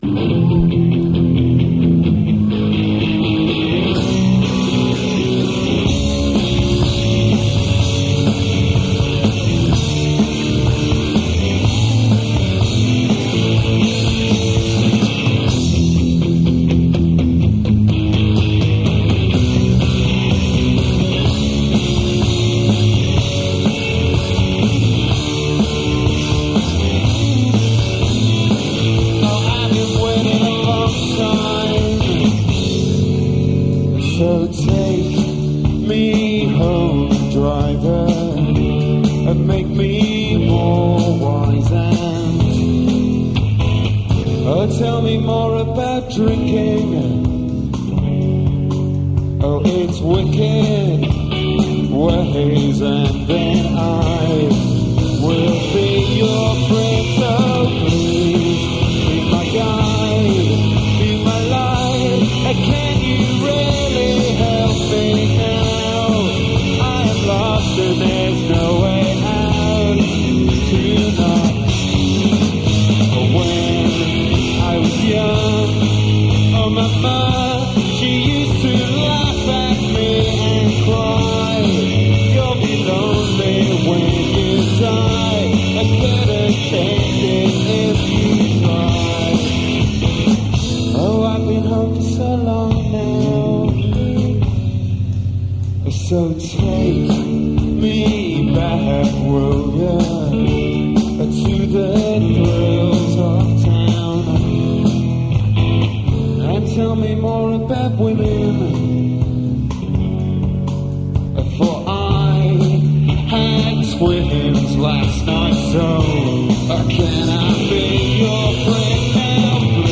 recorded live at La Cigale in Paris on November 3, 1994